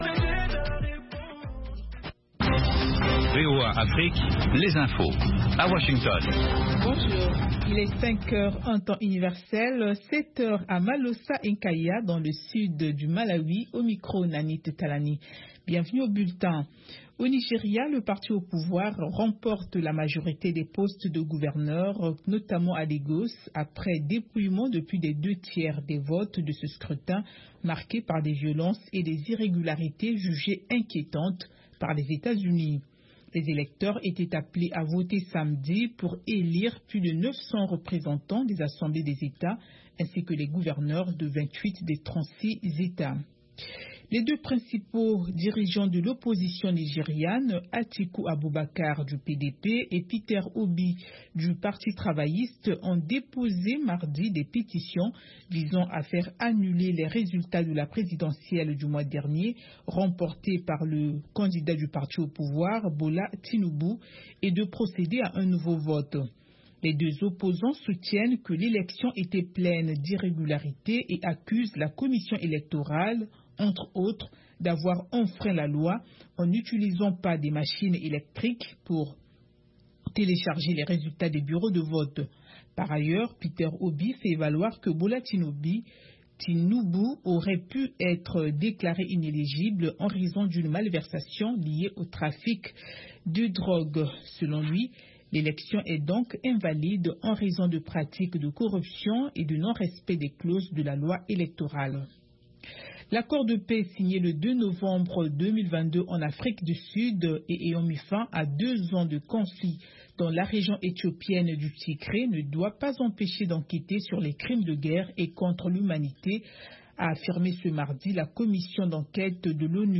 Bulletin
5min Newscast